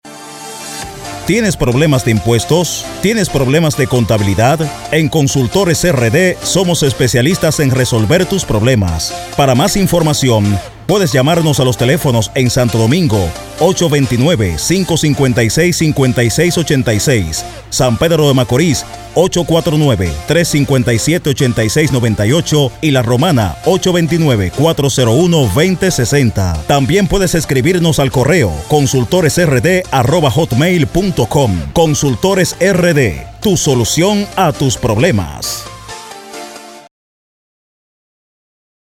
Promo-RADIO-ConsultoresRD.mp3